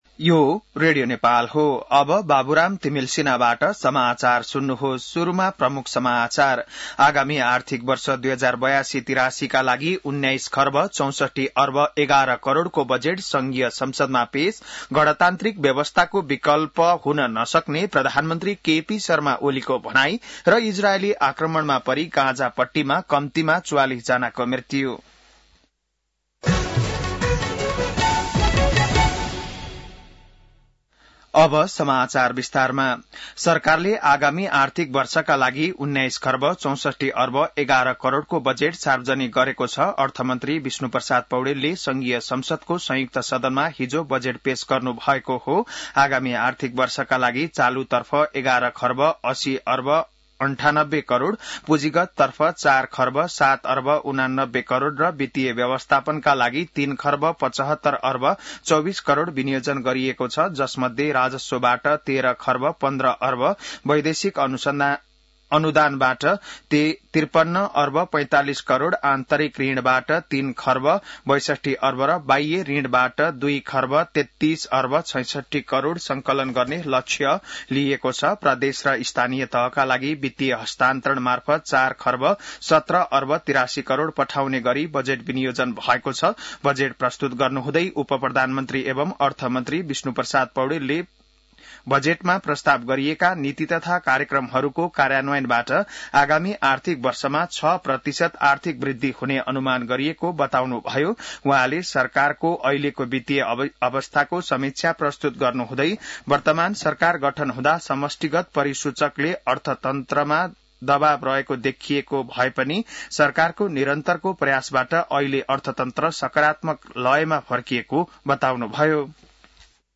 बिहान ९ बजेको नेपाली समाचार : १६ जेठ , २०८२